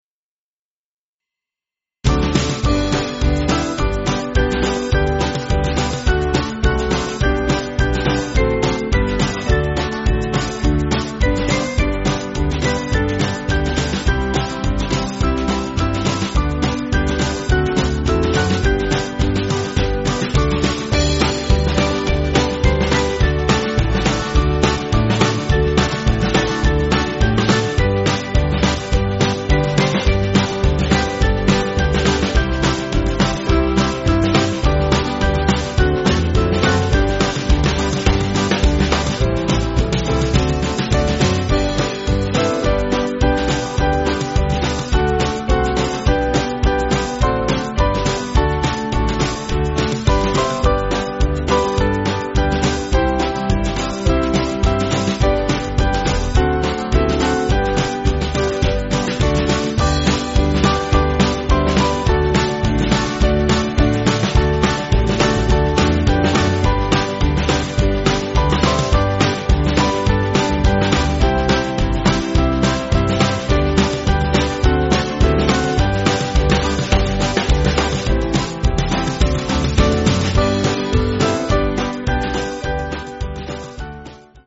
Small Band
(CM)   3/G